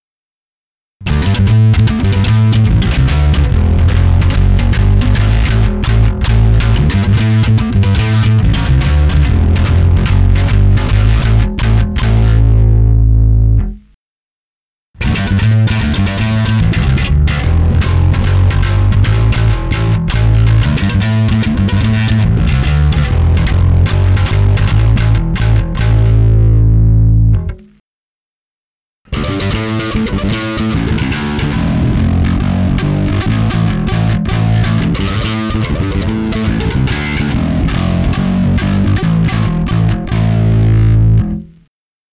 Všechny následující ukázky jsou s plně otevřenou tónovou clonou.
Strašlivě mě s tímto nástrojem bavil overdrive a to hlavně na kobylkový snímač, ten je hodně agresívní. Stejné pořadí snímačů jako výše, hráno prsty.